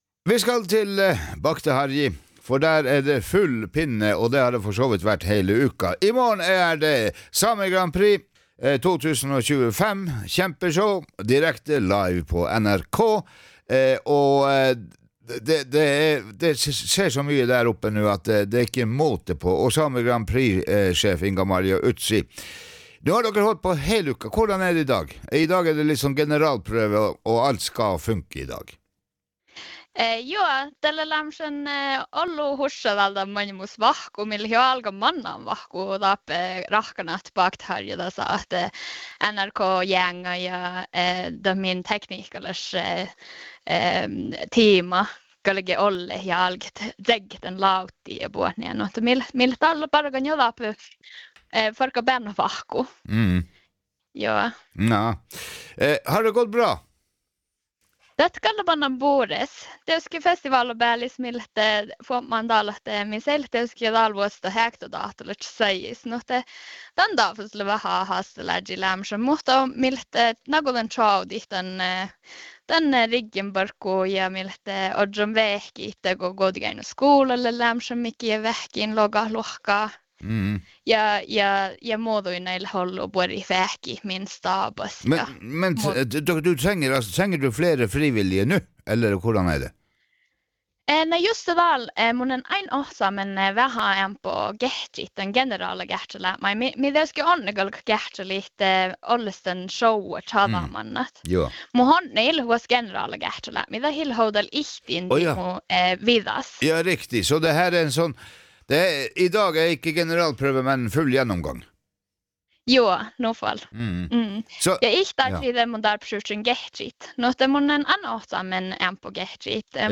Sami-Grand-Prix-2025-fra-hallen_mixdown.mp3